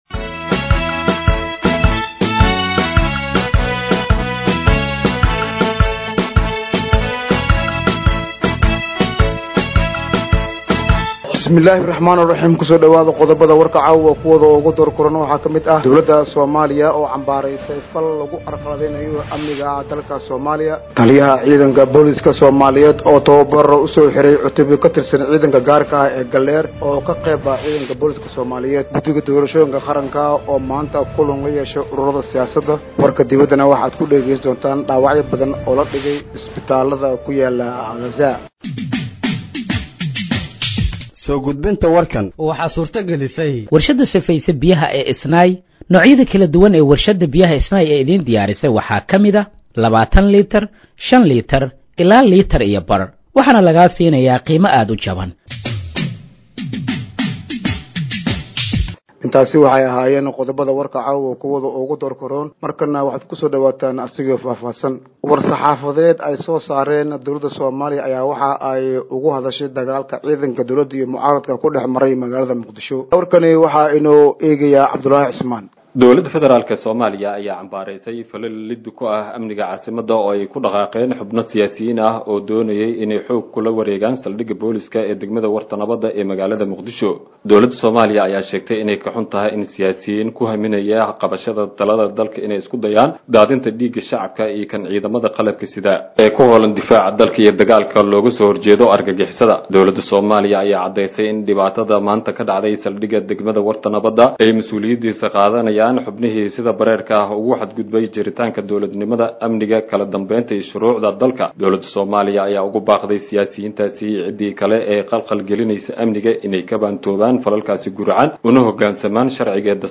Dhageeyso Warka Habeenimo ee Radiojowhar 24/09/2025